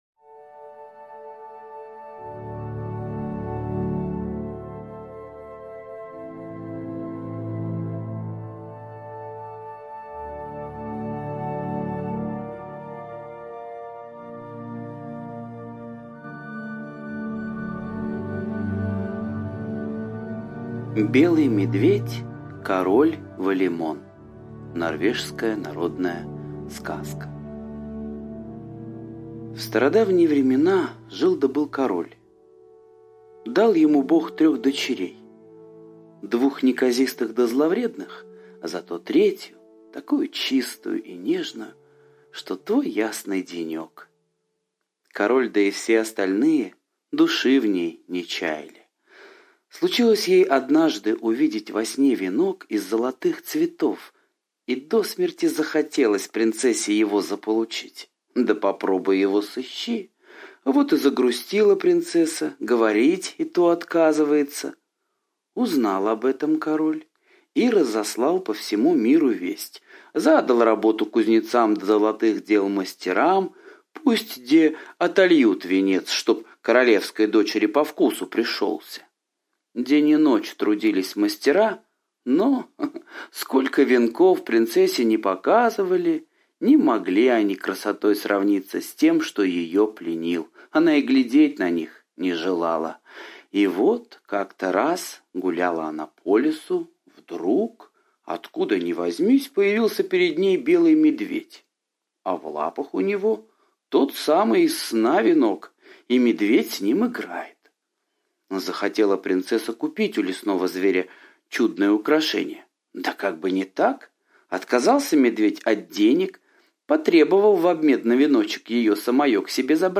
Белый медведь король Валемон - норвежская аудиосказка - слушать онлайн